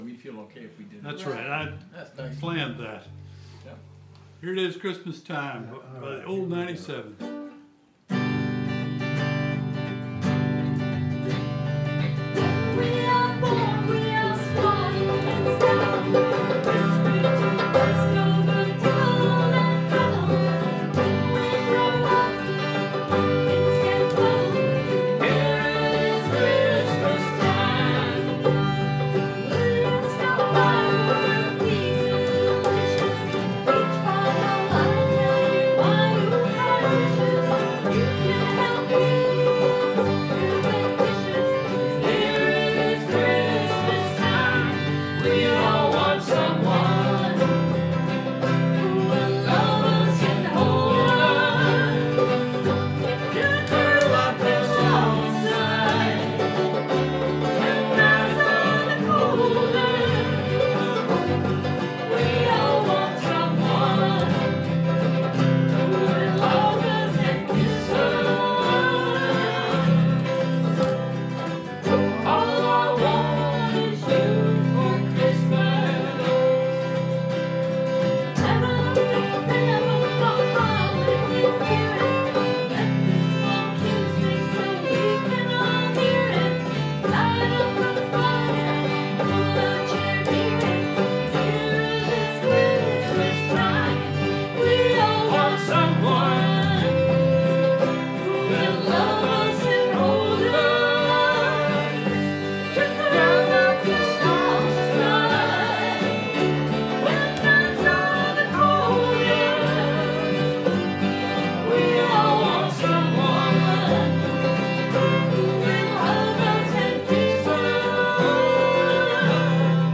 Practice track